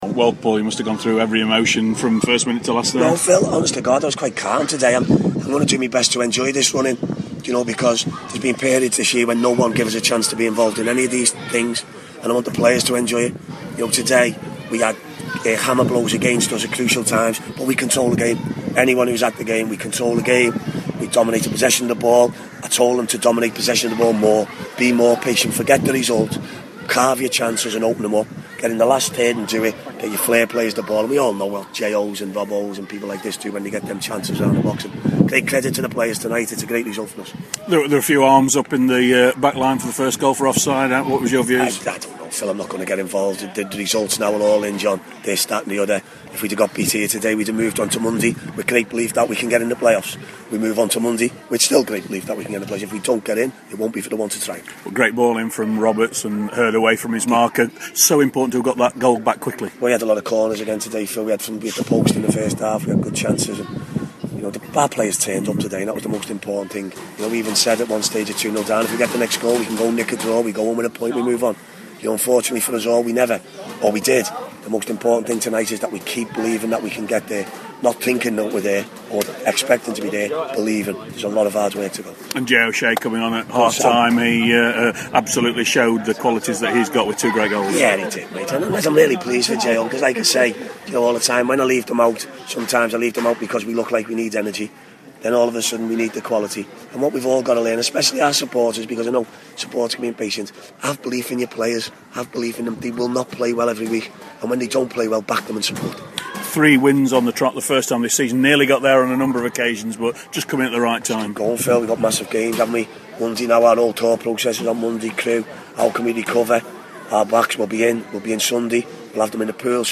INTERVIEW: Paul Cook on Chesterfield's come from behind victory at Yeovil